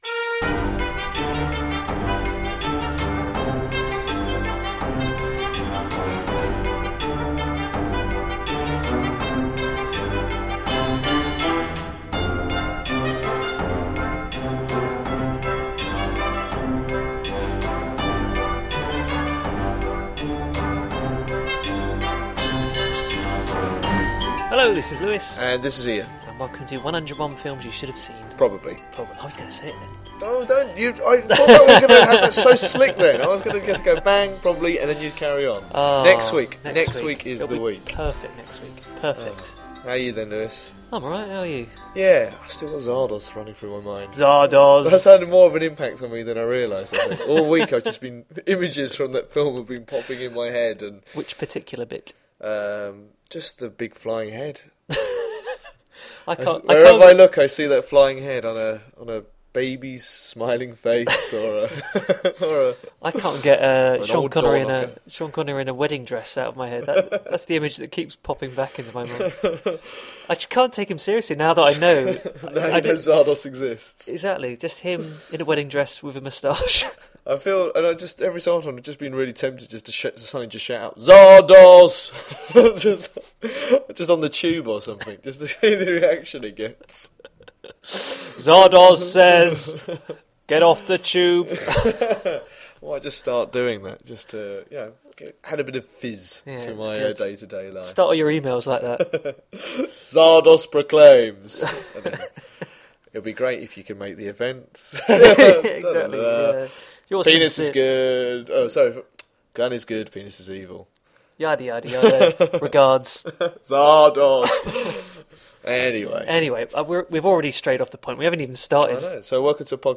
Also listen out for the brand new Trivia Quiz jingle!